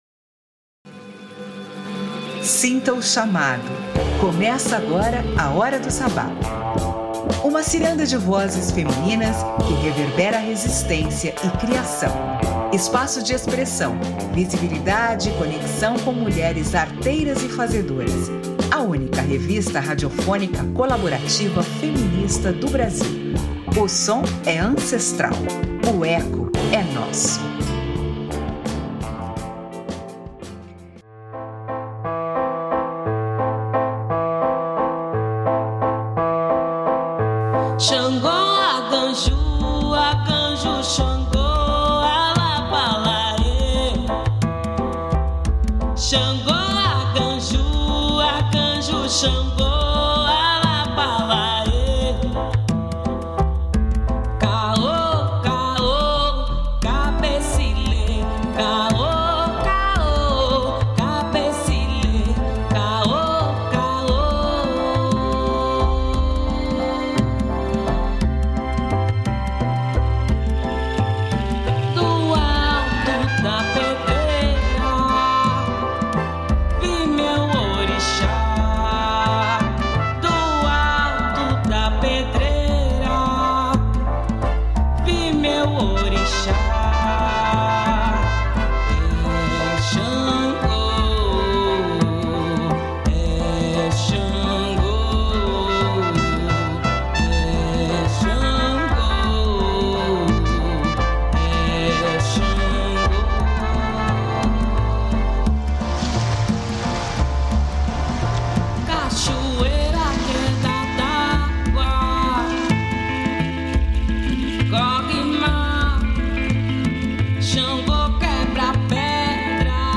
um forró mineiro cheio de jeitim e jogo de palavra.